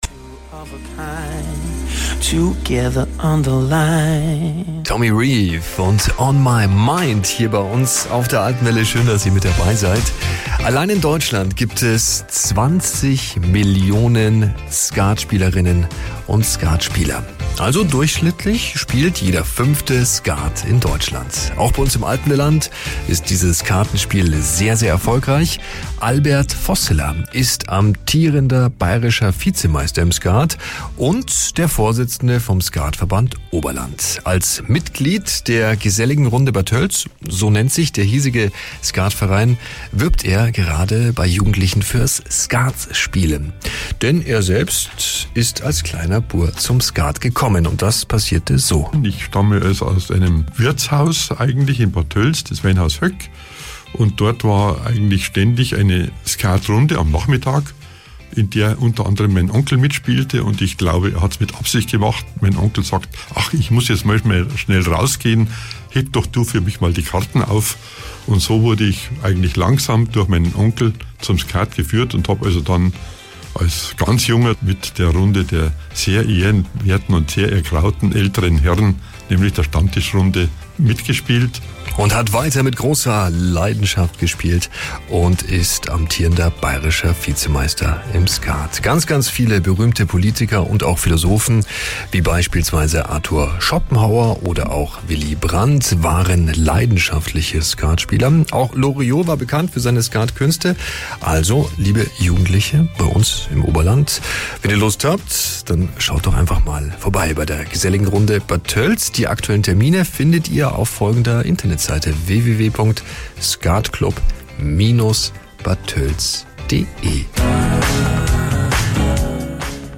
Morgens um 6.10 Uhr ging es los mit den jeweils knapp 2-minütigen Blöcken, die aus dem nahezu einstündigen Interview (siehe auch unten!) zusammengeschnitten wurden.